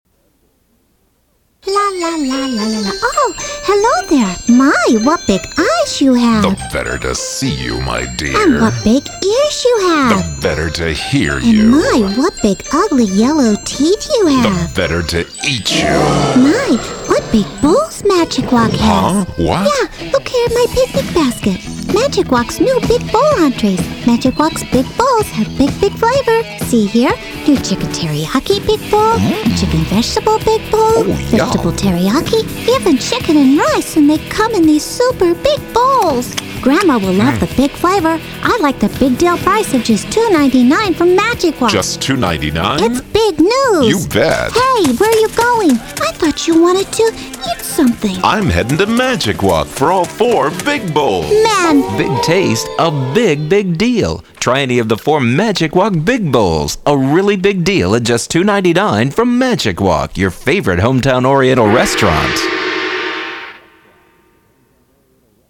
These radio commercials have all won Advertising Addy Awards.
Magic Wok Big Bowls 60 Second radio.mp3